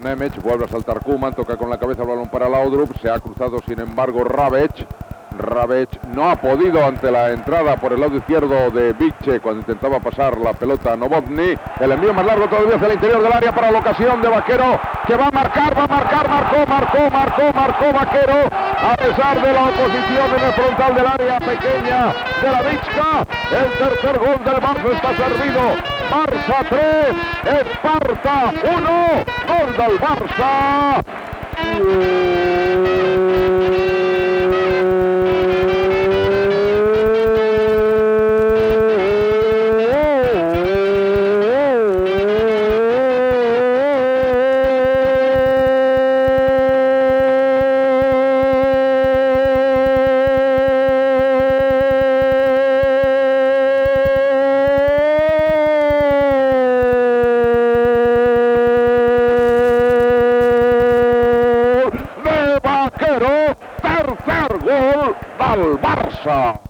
Transmissiò del partit Barça- Sparta Praha, gol de Bakero per fer el 3 a 1.
Esportiu